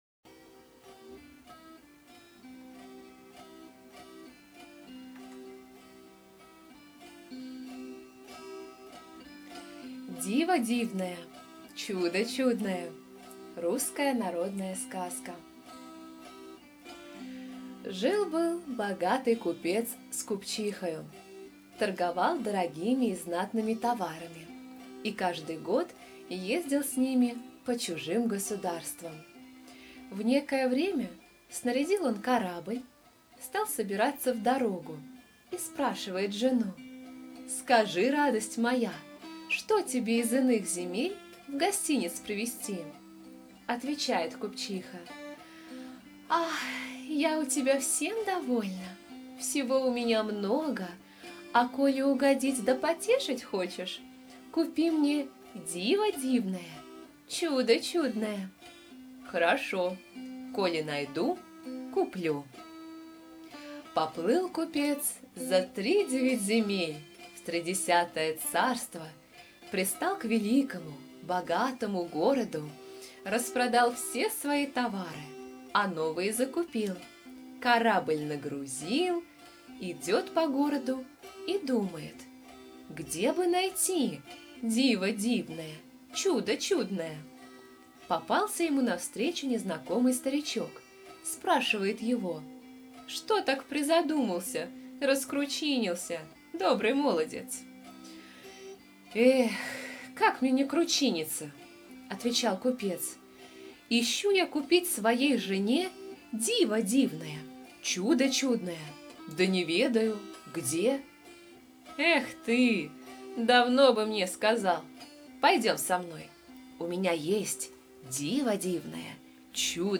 Русская народная аудиосказка «Диво дивное, чудо чудное» требует: любить жену люби да не теряй головы! Навёз купец диковинок заморских, по дальним странам торгуя, не дом стал – музей либо кунсткамера!